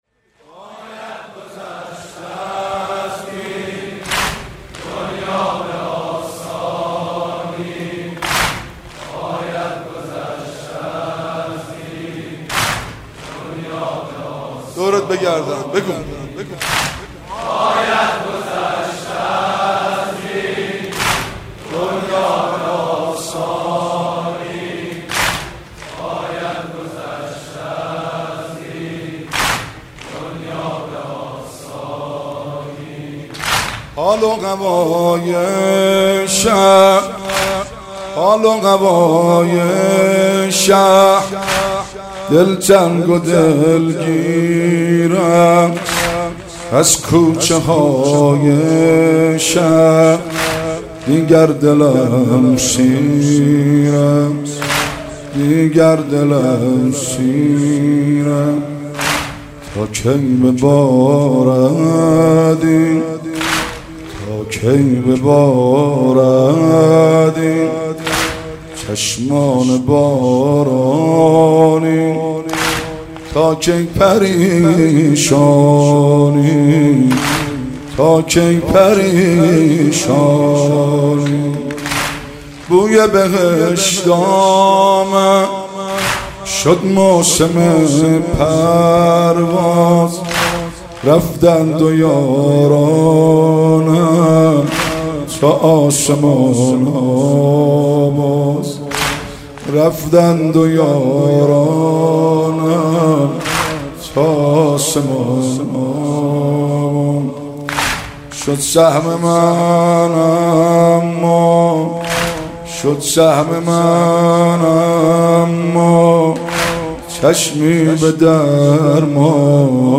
مداحی
نوحه